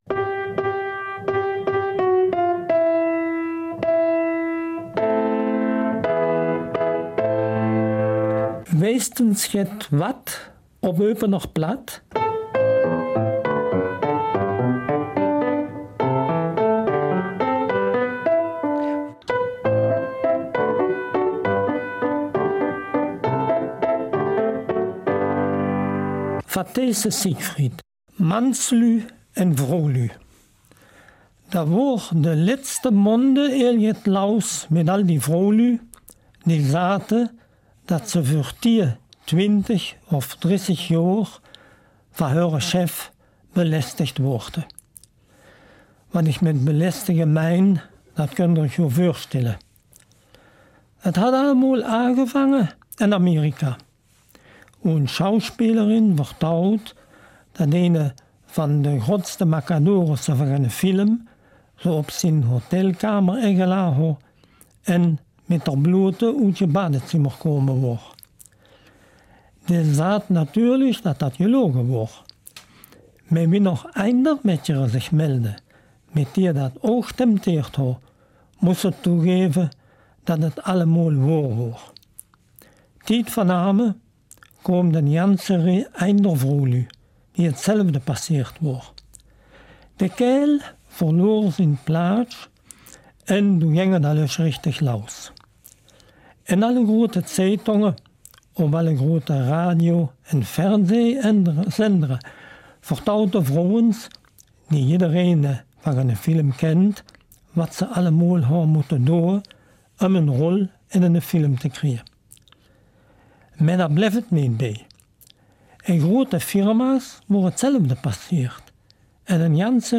Eupener Mundart